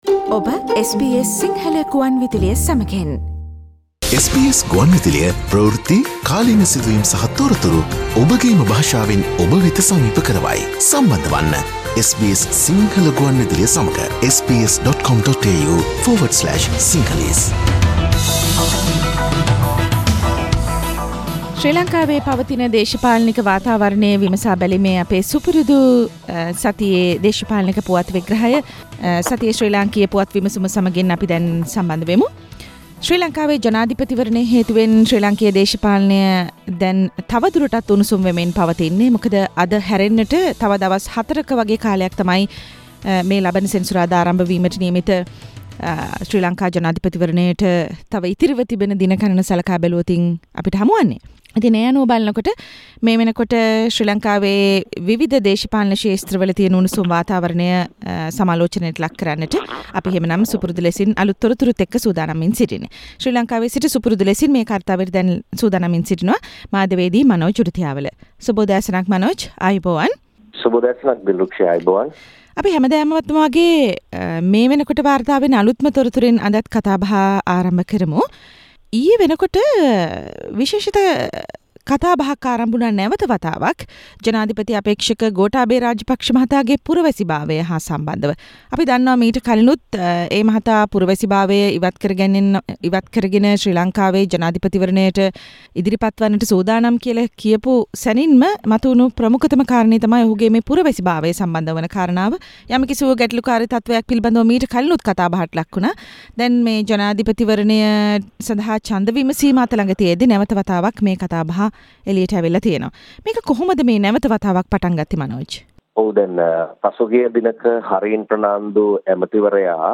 SBS Sinhala weekly news wrap Source: SBS Sinhala